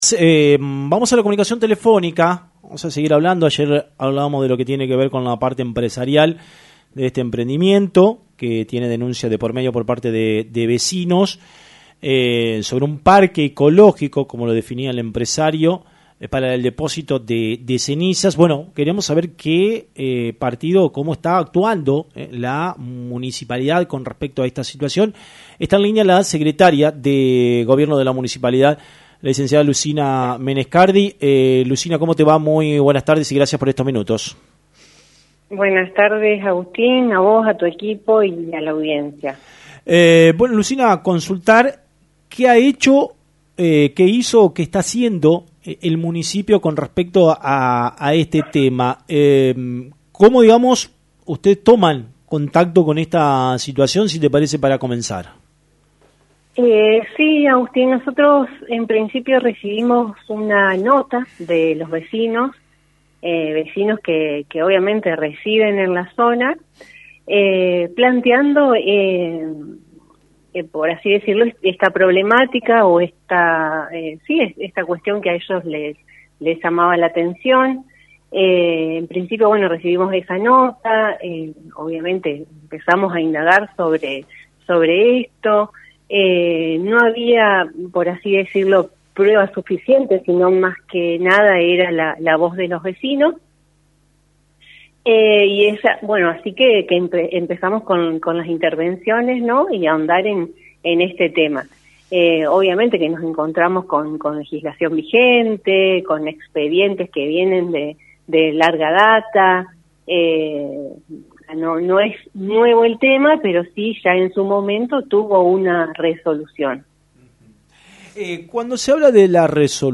Lucina Menescardi – Sec. de Gobierno Municipalidad de Victoria